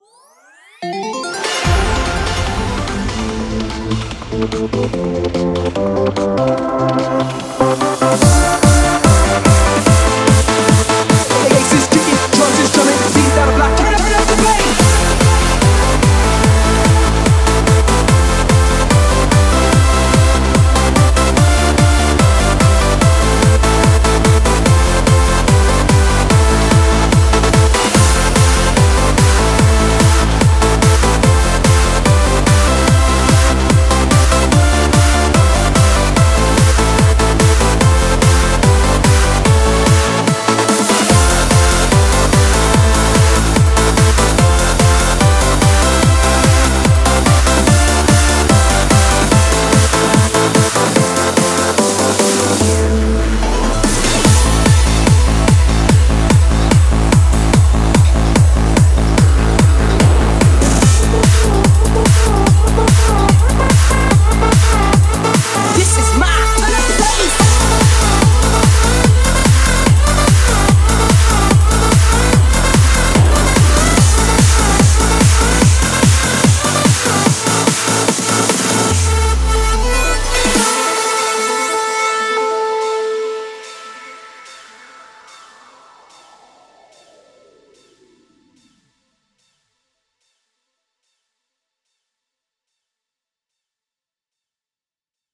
146BPM